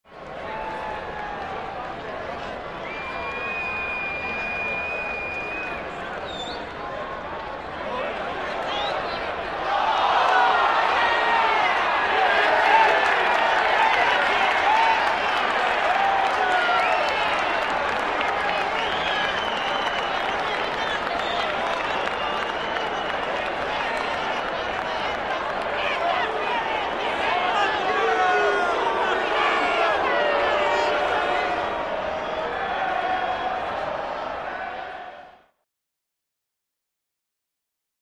Arena Crowd; Crowd, Busy, Boos, Cheers And Chant.